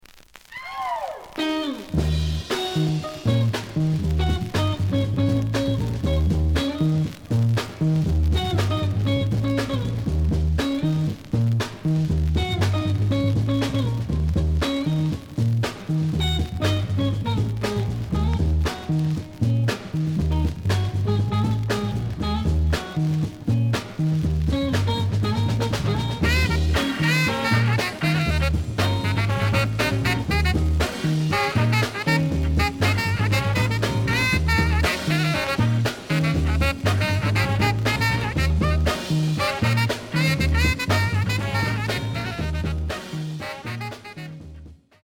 The audio sample is recorded from the actual item.
●Genre: Funk, 60's Funk
Slight noise on A side.